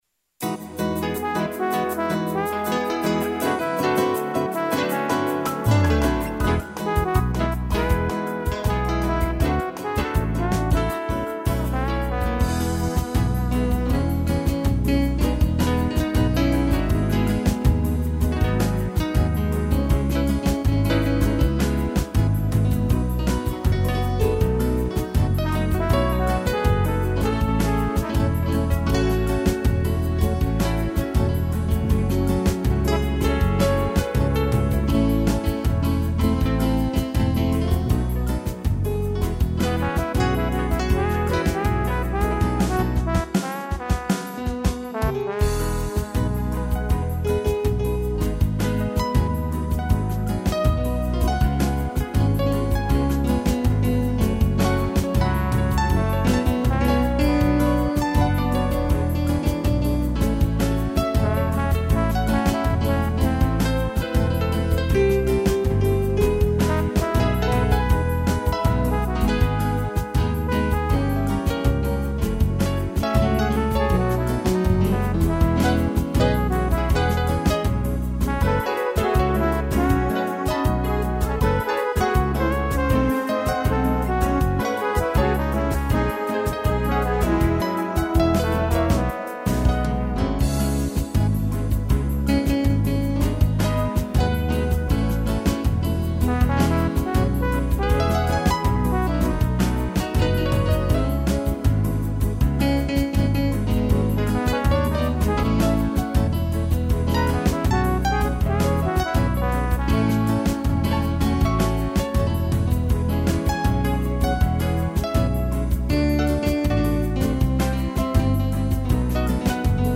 piano e trombone
(instrumental)